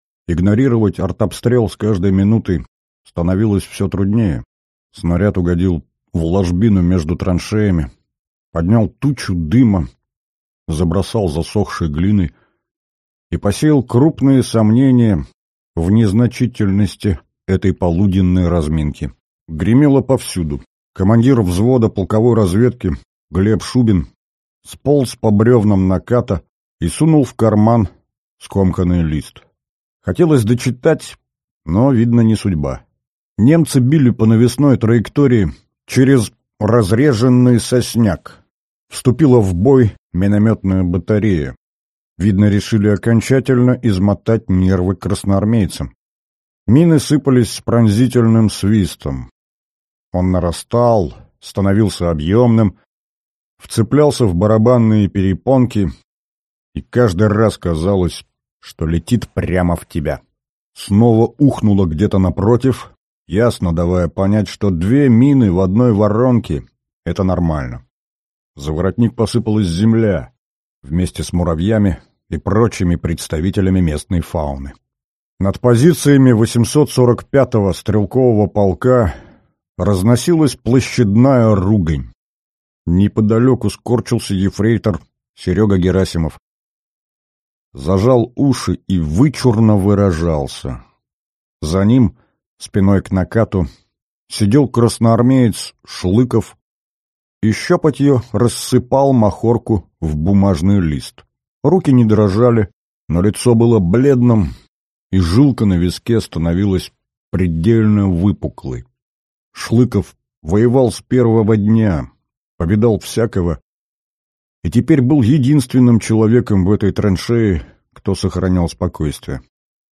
Аудиокнига Рейд ценою в жизнь | Библиотека аудиокниг
Прослушать и бесплатно скачать фрагмент аудиокниги